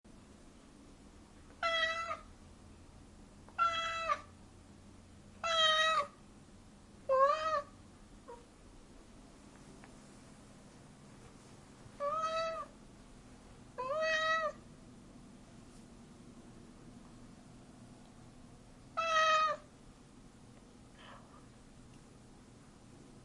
Download Meow sound effect for free.
Meow